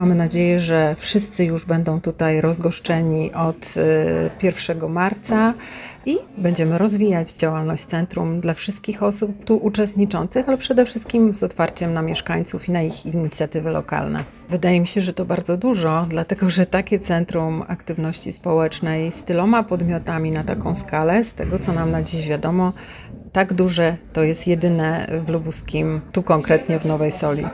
– Chcemy, aby ten budynek tętnił życiem, również za sprawą organizacji pozarządowych – powiedziała Natalia Walewska – Wojciechowska, wiceprezydent Nowej Soli: